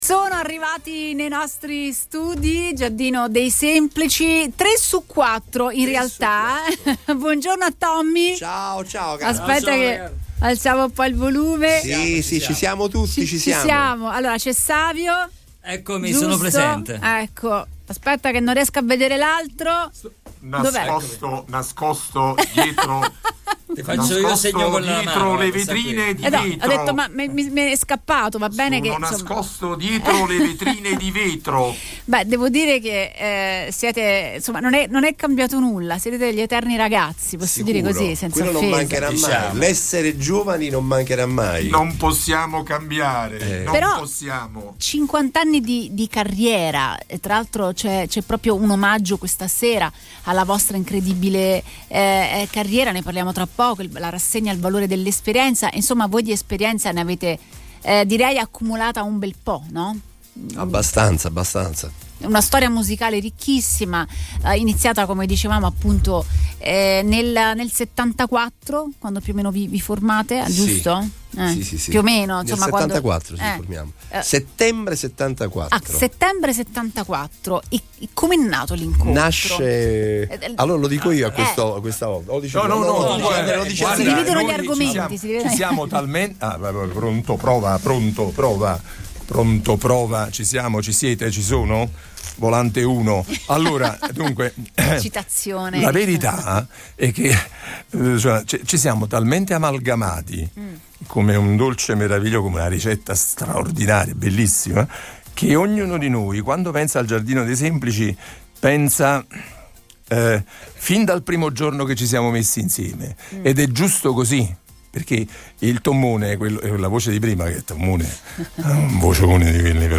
Questa mattina, il Giardino dei Semplici, una delle band più longeve della musica italiana, è stata ospite negli studi di Radio Punto Nuovo per un’intervista esclusiva.